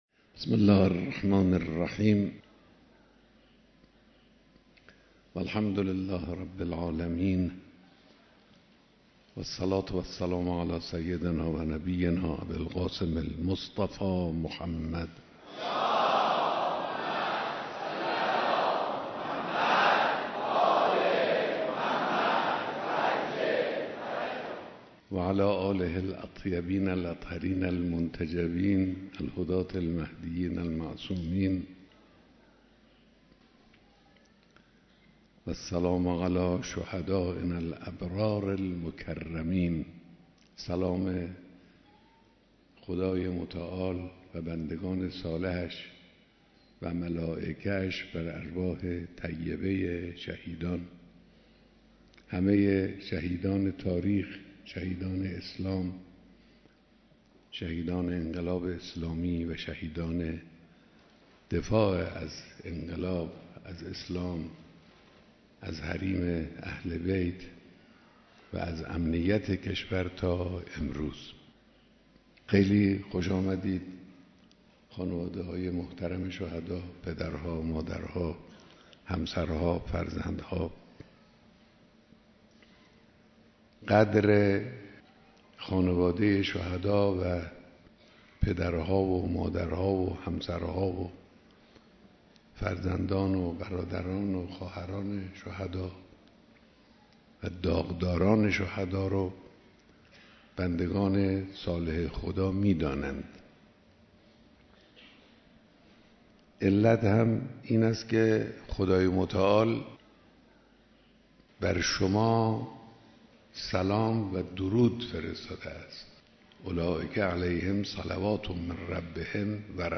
بیانات در دیدار جمعی از خانواده‌های شهیدان